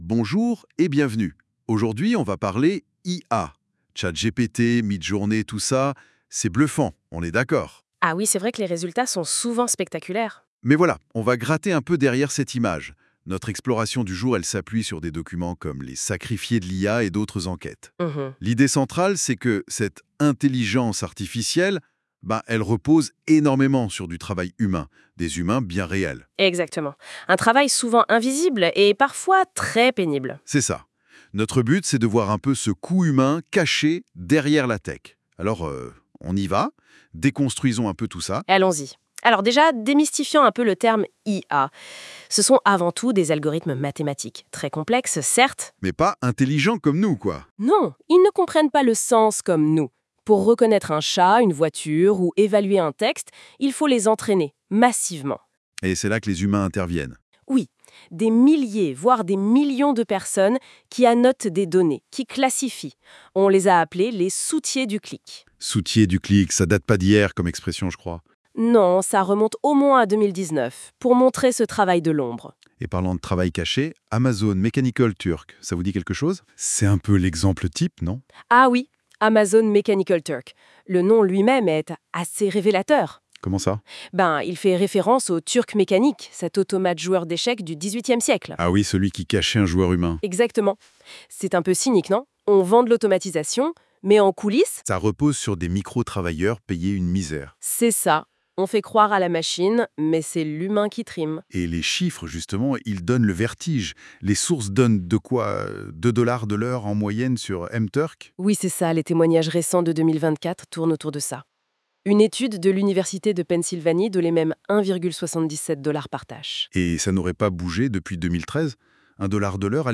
Un résumé de cet article par NotebookLM Un podcast de cet article par NotebookLM Dernières nouvelles Après avoir écrit cet article j'ai découvert sur Youtube une vidéo sur le même sujet, mais vu du côté des Etats-Unis essentiellement.